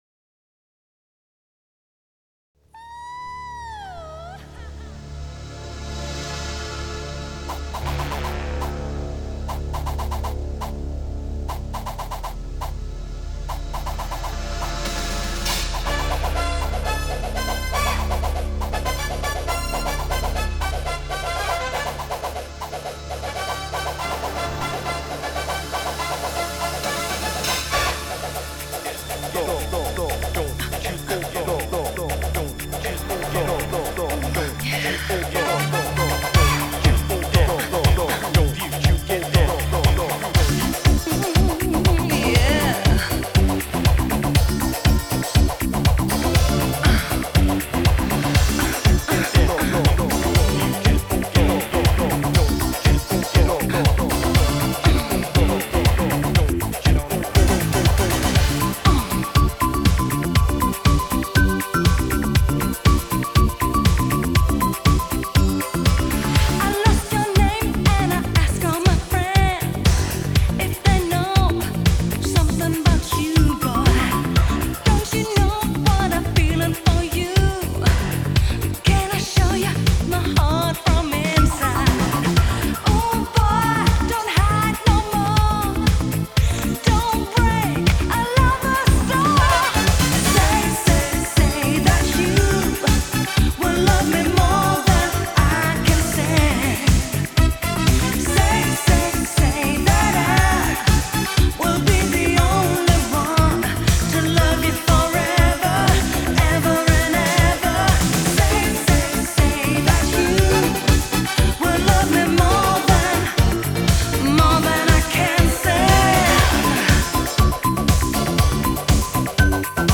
类型：Disco，Hi-NRG